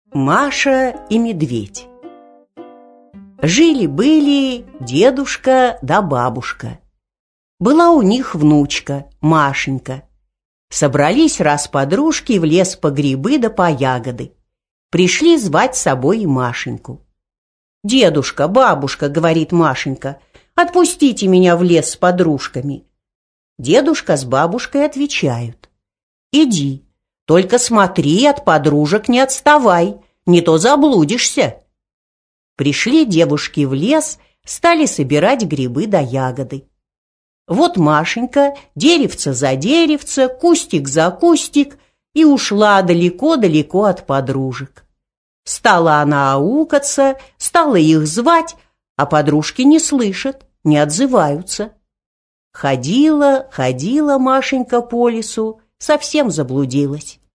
Аудиокнига Маша и Медведь | Библиотека аудиокниг